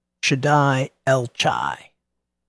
shah-dai el-chai
shah-dai-el-chai.mp3